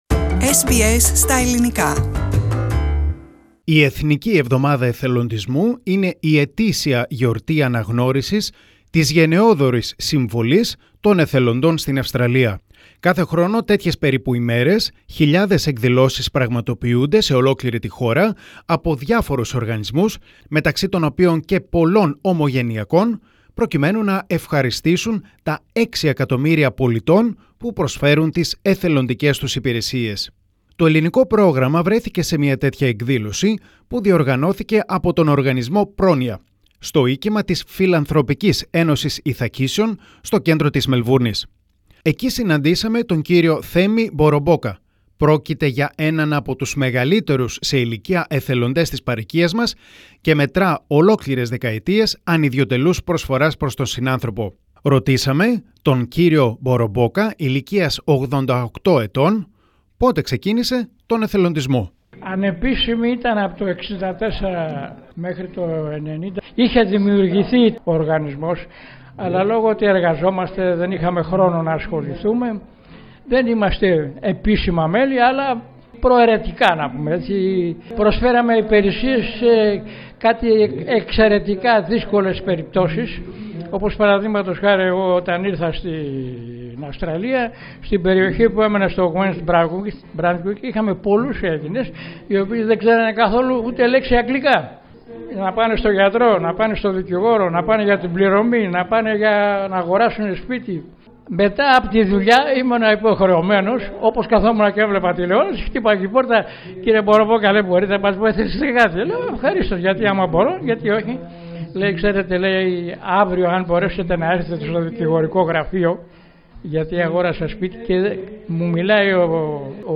Το Ελληνικό Πρόγραμμα βρέθηκε σε μια τέτοια εκδήλωση, που διοργανώθηκε από τον οργανισμό «Πρόνοια» στο οίκημα της Φιλανθρωπικής Ένωσης Ιθακήσιων στο κέντρο της Μελβούρνης.